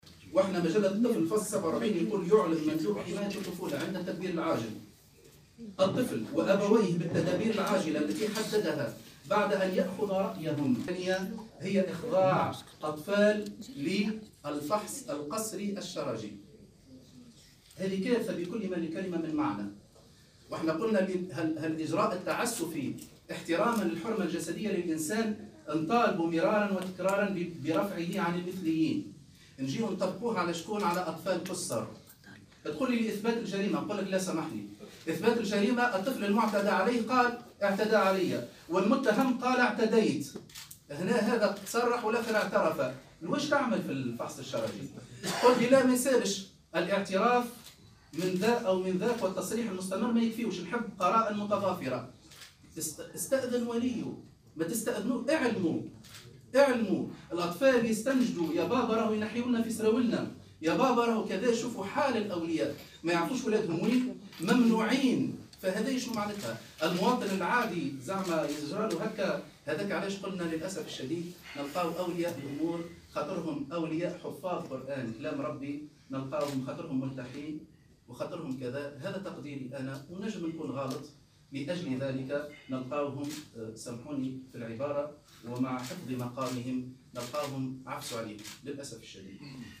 وأضاف خلال ندوة صحفية عقدتها الهيئة اليوم السبت أن ما تم القيام به "إجراء تعسفي"، مؤكدا أنه سيتم تتبع كل من أذن بإجراء الفحص الشرجي للأطفال.